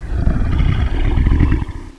groan2.wav